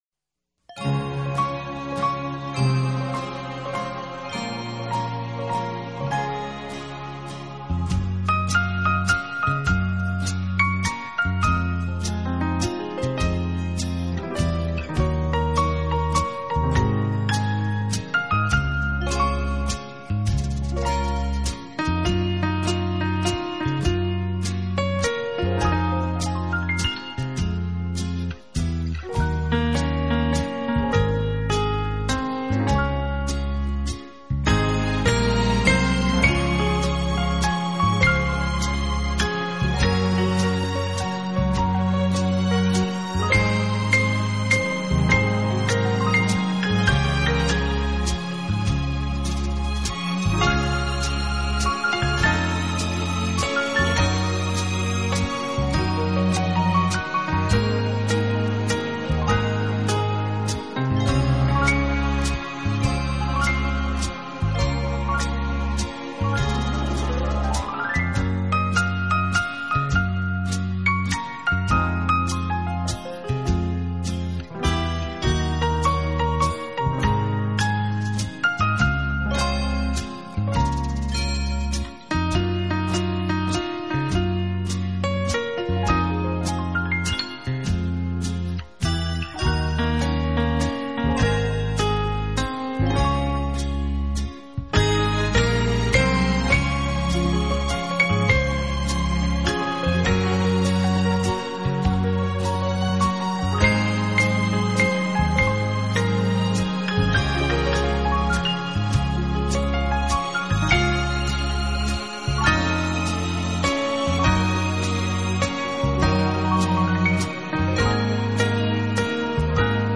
整张专辑全部都是以浪漫情调为主题的钢琴合辑，当熟